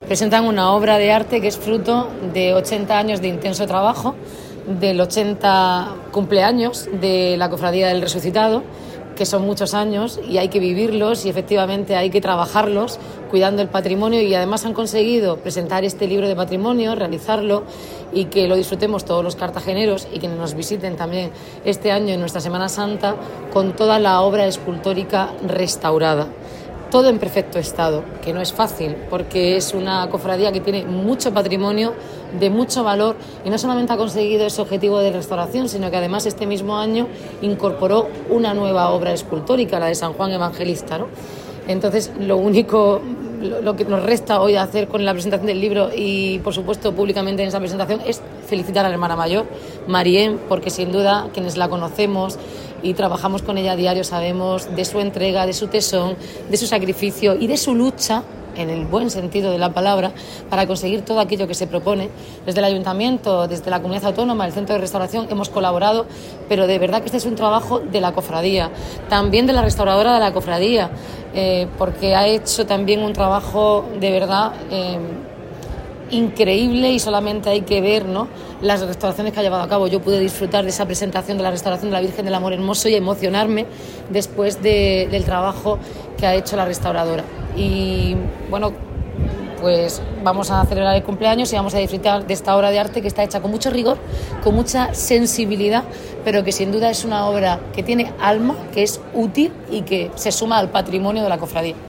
Enlace a Declraciones de la alcaldesa, Noelia Arroyo, presnetación del libro MAIESTAS